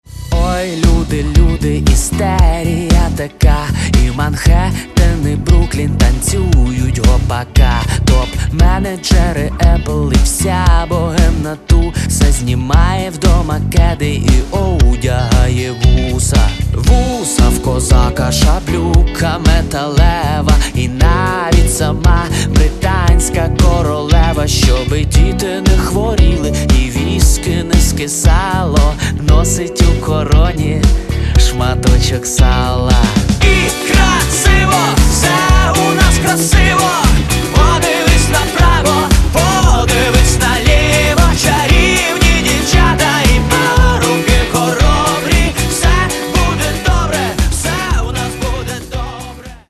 Каталог -> Рок и альтернатива -> Просто рок